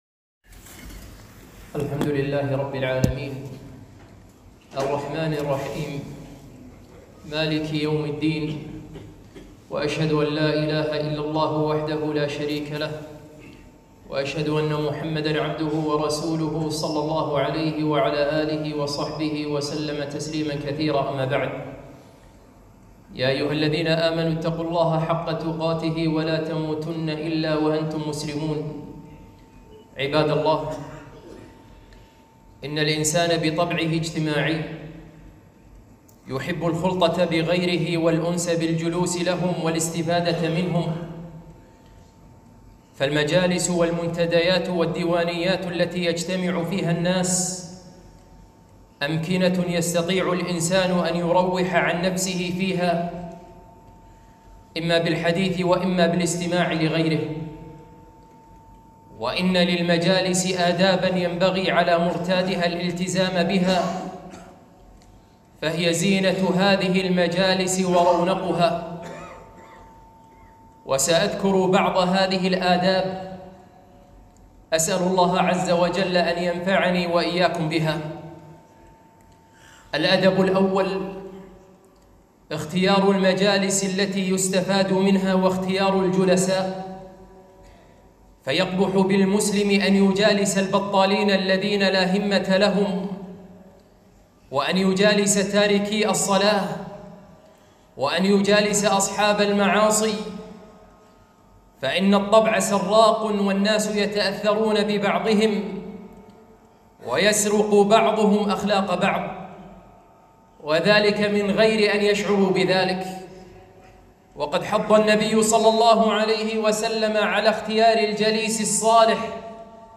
خطبة - آداب المجالس